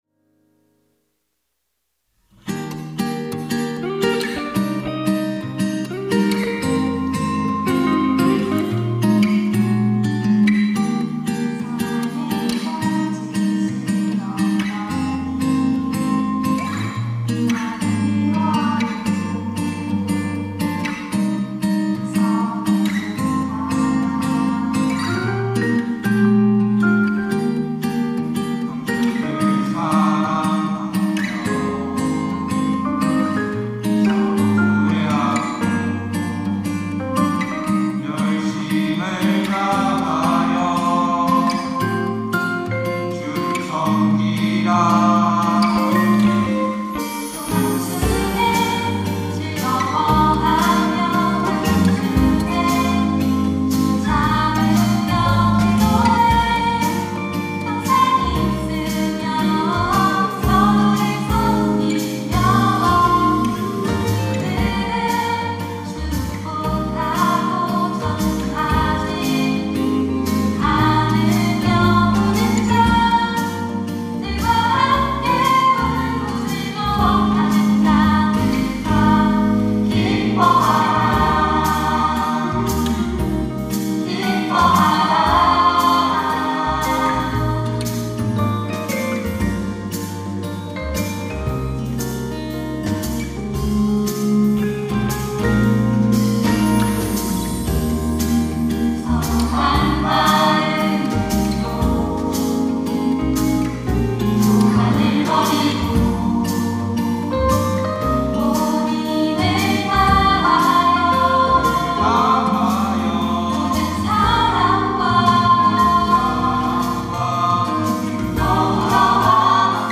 특송과 특주 - 사랑엔 거짓이 없나니
청년부 4팀 리더십